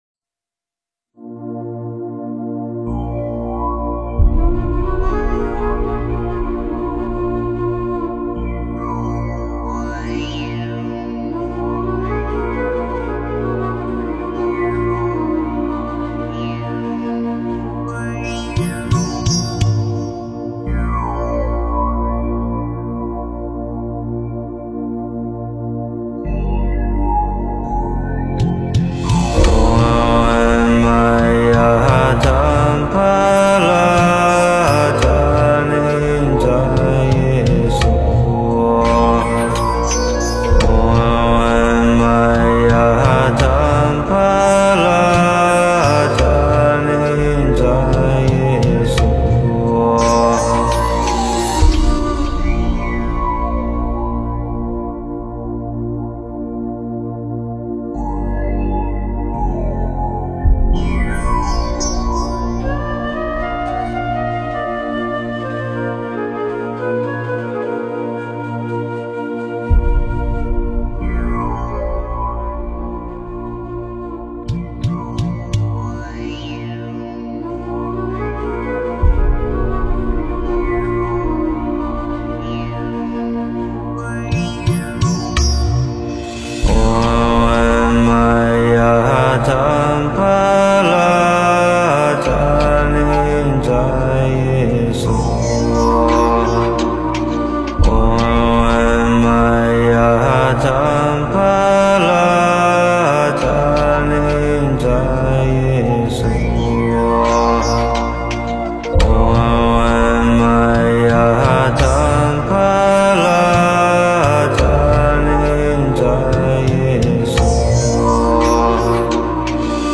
黄财神心咒 诵经 黄财神心咒--群星 点我： 标签: 佛音 诵经 佛教音乐 返回列表 上一篇： 憨山大师劝世文 下一篇： 迦蓝尊者咒 相关文章 圣无量寿决定光明王陀罗尼--佚名 圣无量寿决定光明王陀罗尼--佚名...